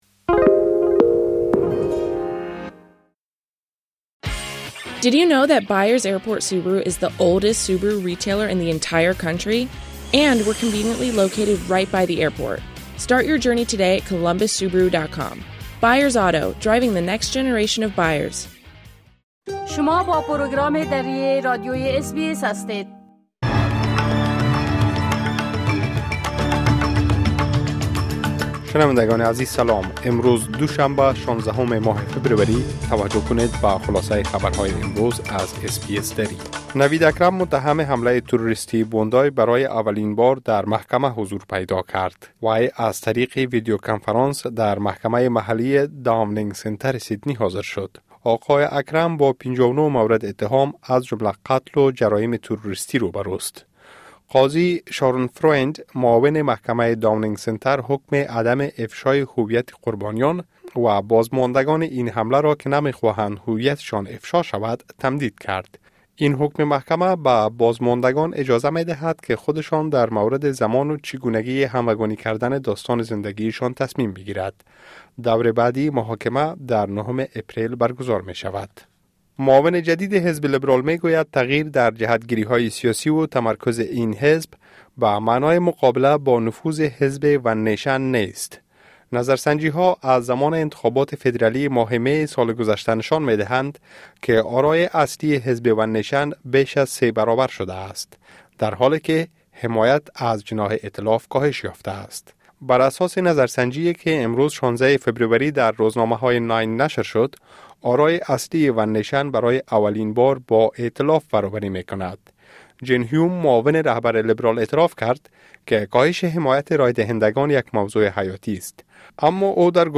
خلاصه‌ای مهم‌ترين خبرهای روز | ۱۶ فبروری